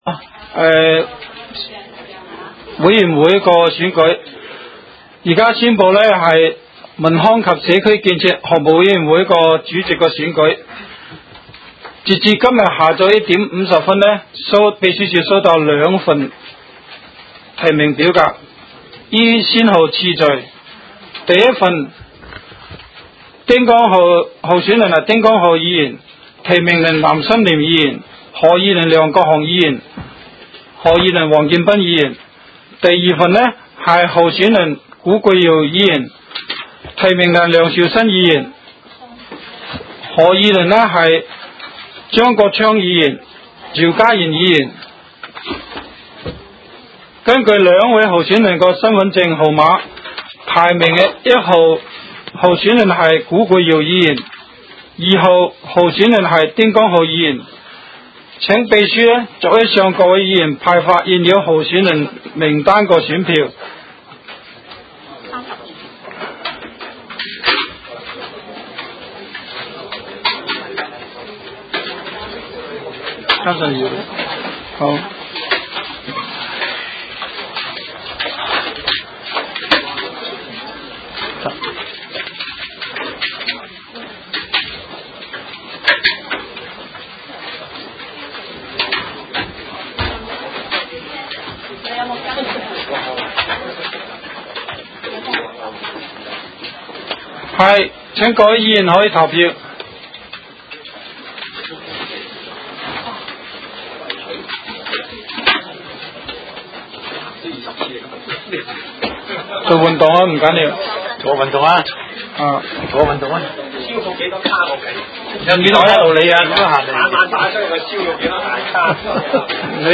委员会会议的录音记录
地点: 香港西湾河太安街29号 东区法院大楼11楼 东区区议会会议室